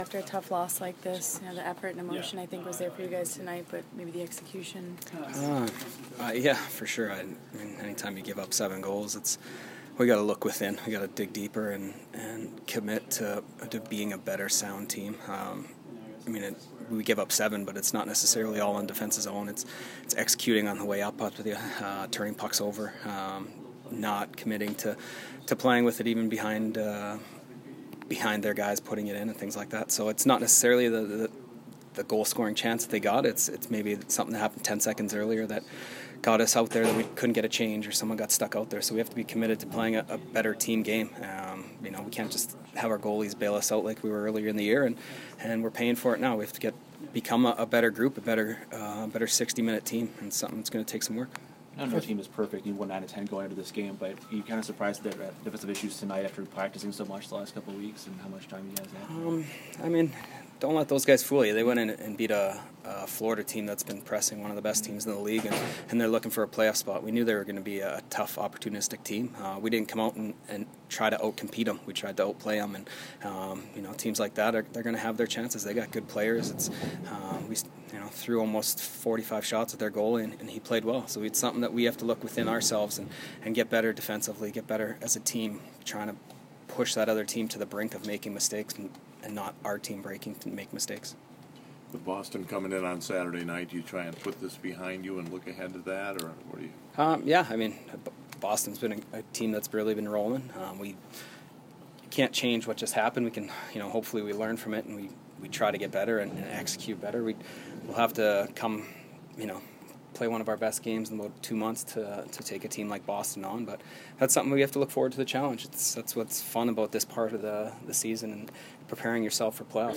Chris Kunitz post-game 3/13